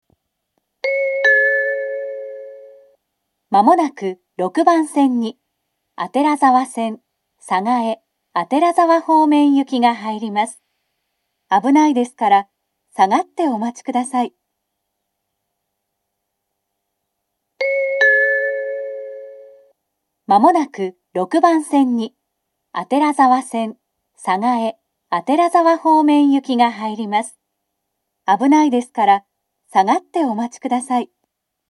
６番線下り接近放送